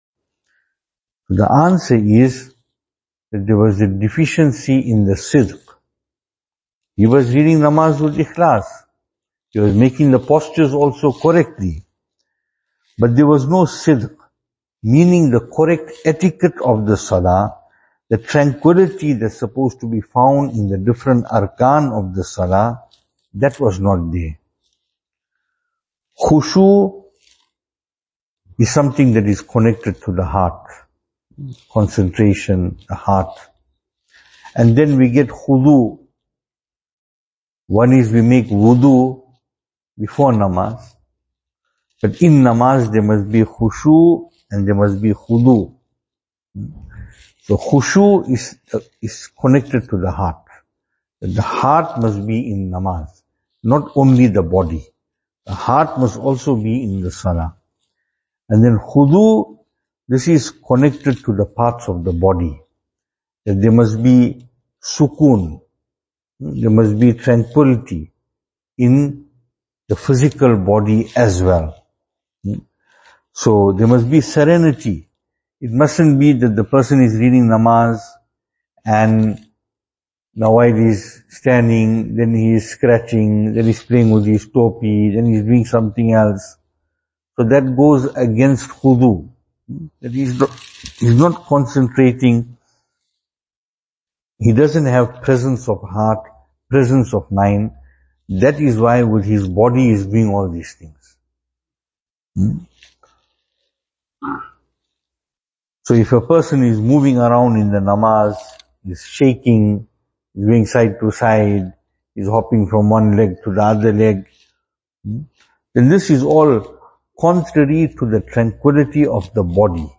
2023-12-18 Khushu And Khudhu In Salaah Venue: Albert Falls , Madressa Isha'atul Haq Service Type: Zikr « Allah Ta’ala Is In Full Control Of Everything Including Gazza.